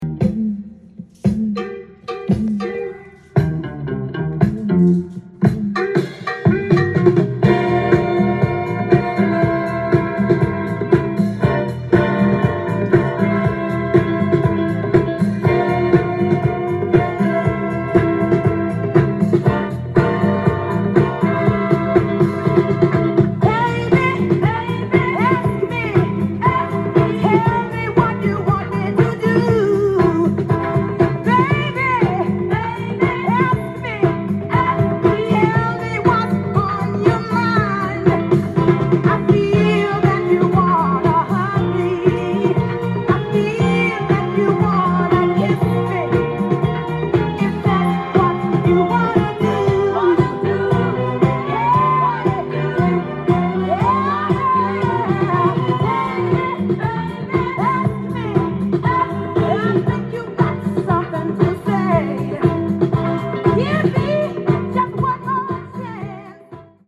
ジャンル：Soul-7inch-全商品250円
店頭で録音した音源の為、多少の外部音や音質の悪さはございますが、サンプルとしてご視聴ください。
音が稀にチリ・プツ出る程度